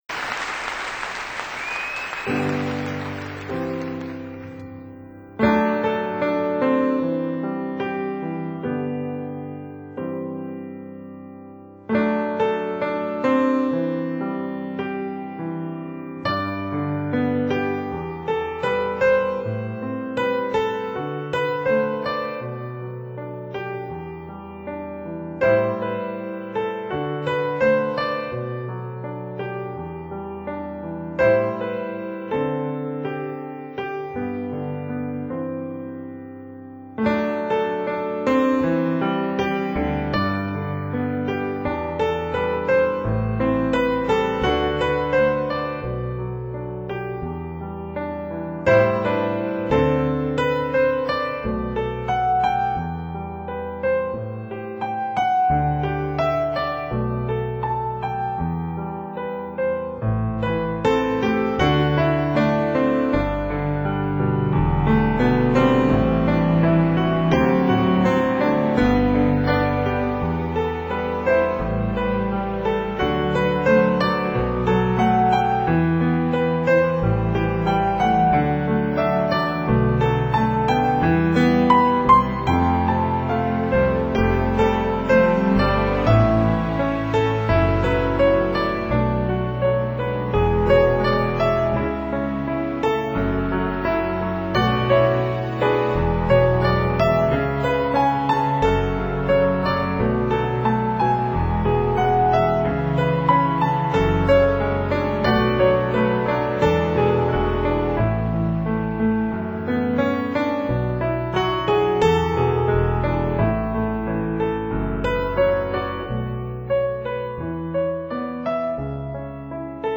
recorded live in Salt Lake City in March, 2000.